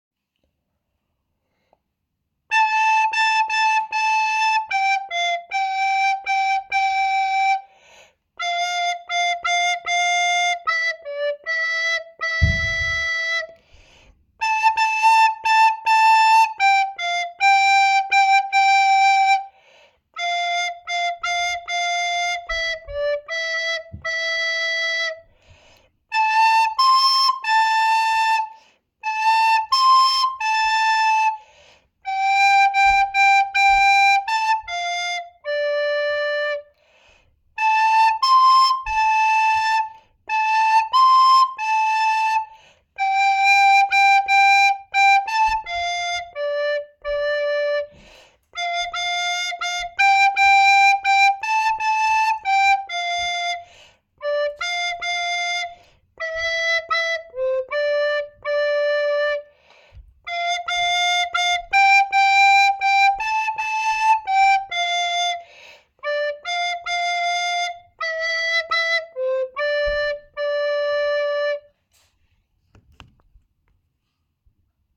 Sudet nokkahuilu (koko laulu)